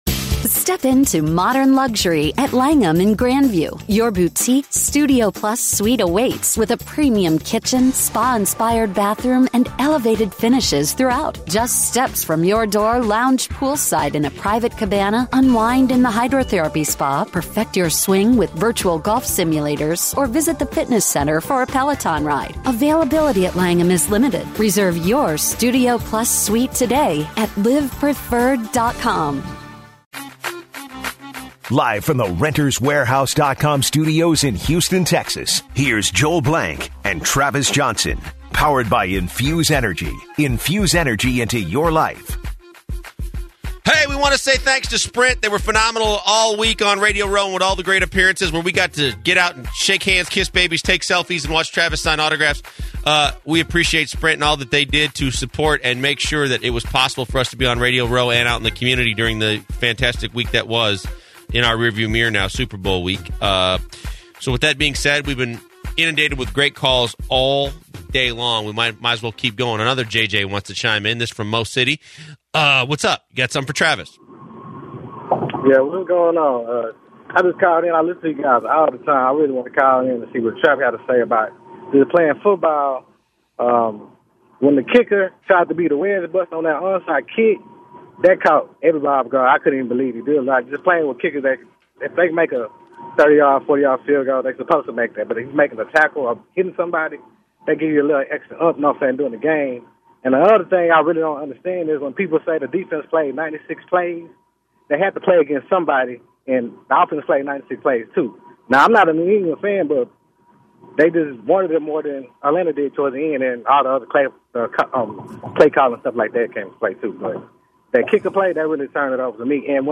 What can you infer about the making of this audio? In the final hour, the calls kept coming starting with a conversation about Superbowl participants to come from the Houston area. The guys talked about Brady and Belichick's legacy. The show finished with a discussion about the NFL Hall of Fame inductees.